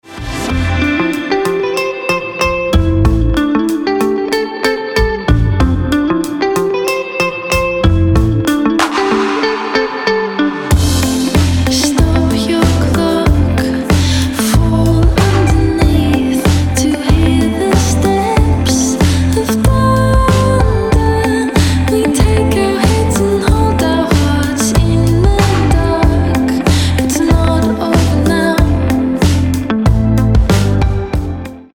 • Качество: 320, Stereo
красивые
женский вокал
мелодичные